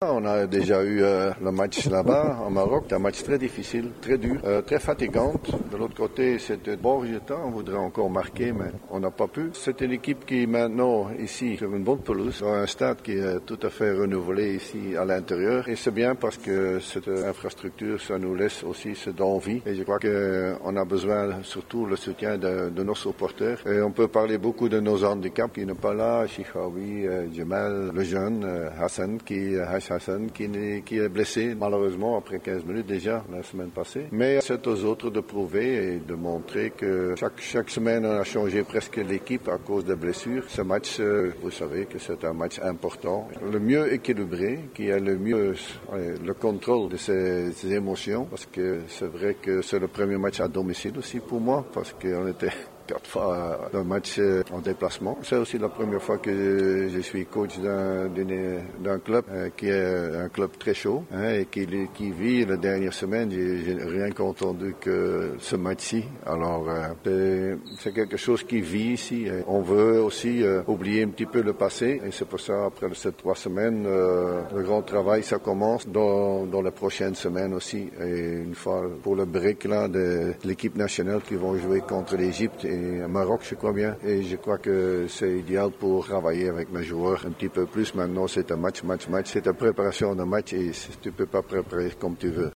عقد مدرب النجم الرياضي الساحلي, البلجيكي جورج ليكانز اليوم الاربعاء 07 نوفمبر 2018 ندوة صحفية تحدث من خلالها عن مدى جاهزية الفريق لمباراة إياب ثمن نهائي كاس زايد للأندية الابطال.